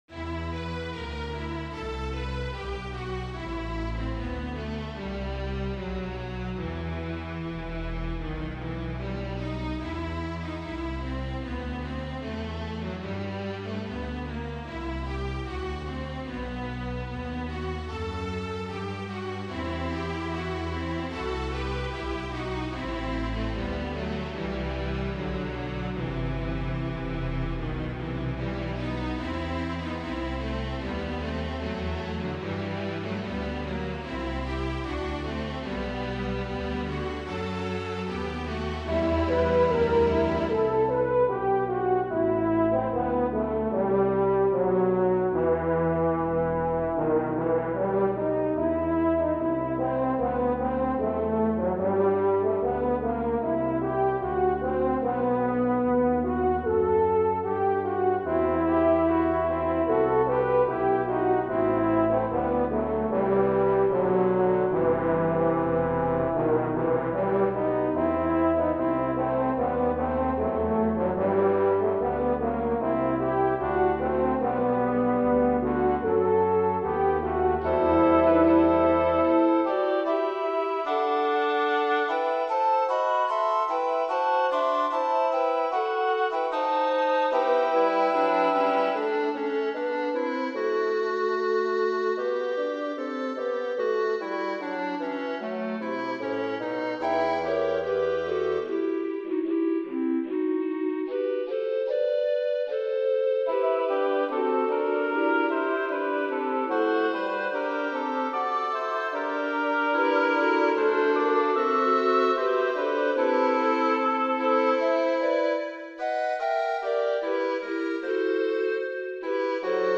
Roy Howard has composed a new work for orchestra that matches the theme of a 2008 GPAC gallery exhibit.
Flute, Soprano Recorder, Alto Recorder, Bb Clarinet, Oboe, Bassoon
Trumpets 1,2; French Horn, Trombone, Tuba
Violins 1,2; Viola, Cello, Bass